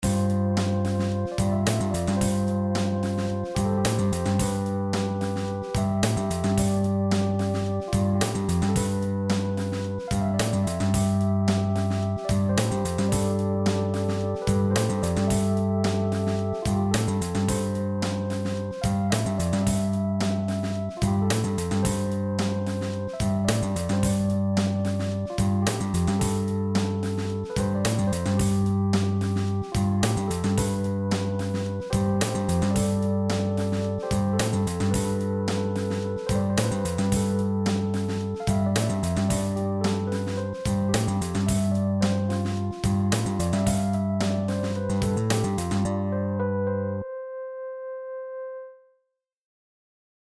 Deze audiowerken zijn gemaakt met FL Studio en ingespeeld met een USB MIDI-keyboard
PianoSong.mp3